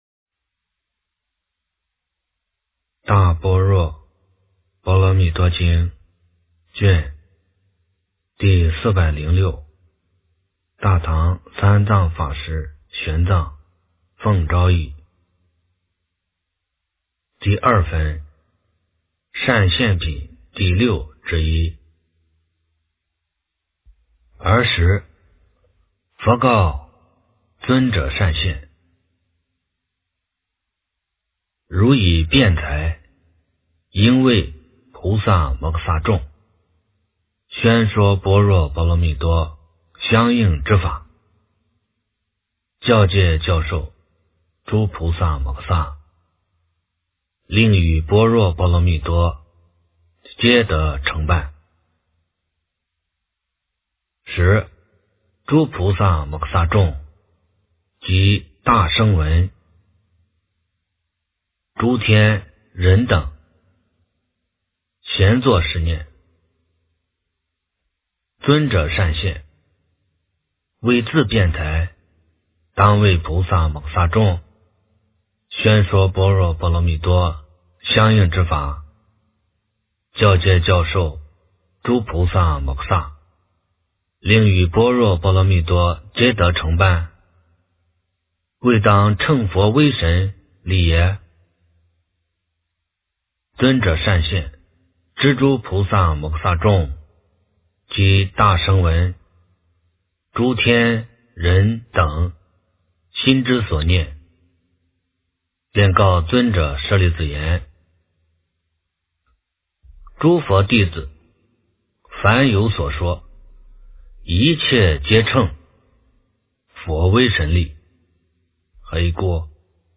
大般若波罗蜜多经406卷 - 诵经 - 云佛论坛